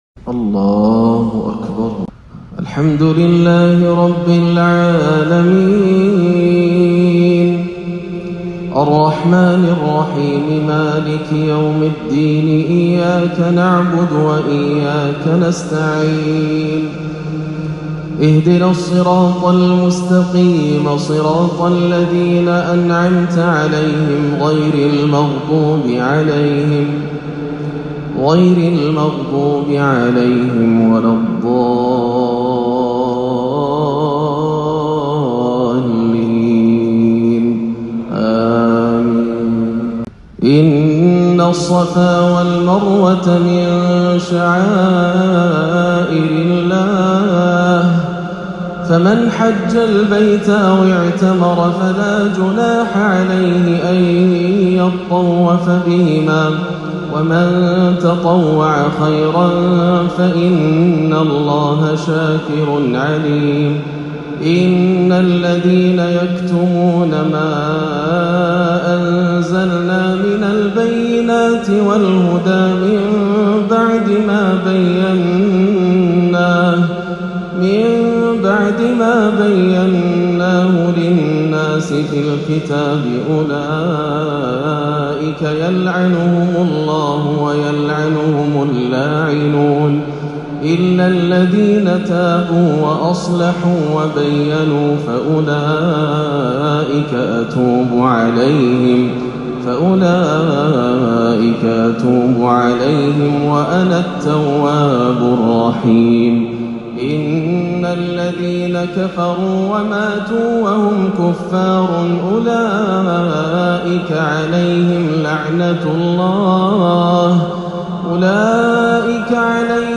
(ربنا ما خلقت هذا باطلا سبحانك) تلاوة خاشعة من سورتي البقرة وآل عمران - الأحد 9-11 > عام 1439 > الفروض - تلاوات ياسر الدوسري